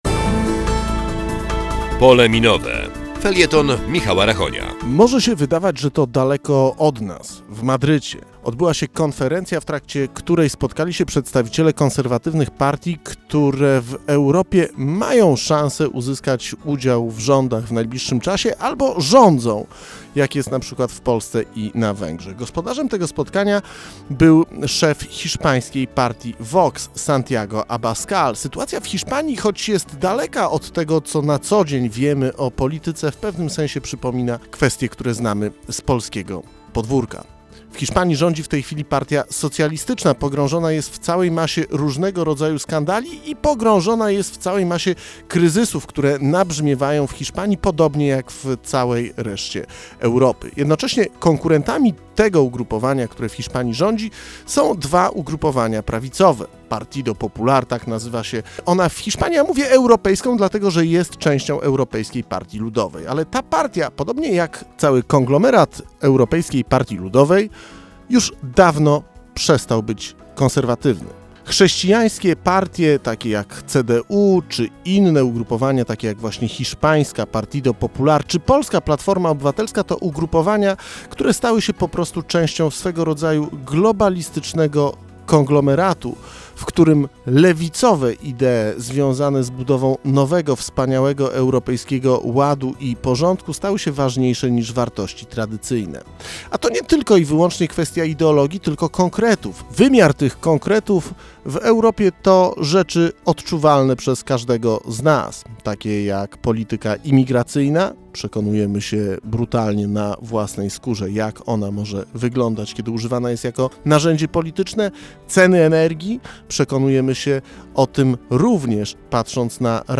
"Pole Minowe" - felieton polityczny